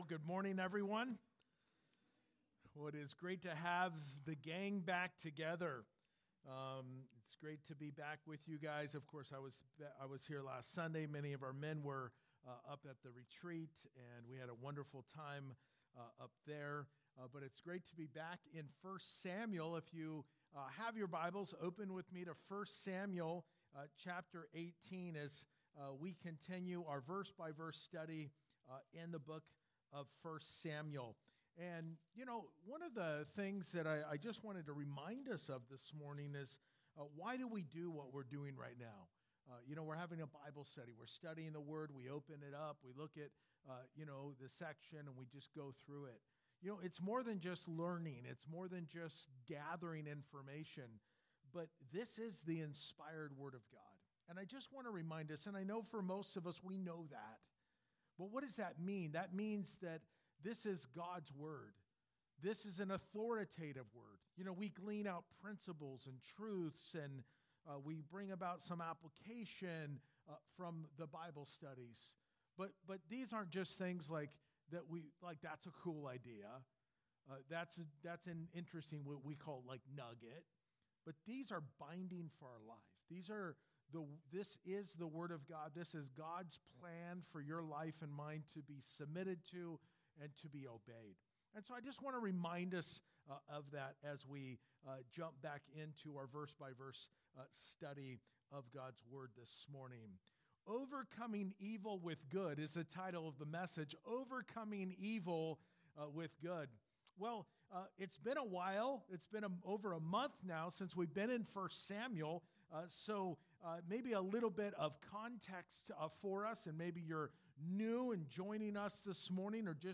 Overcoming+Evil+with+Good+2nd+service.mp3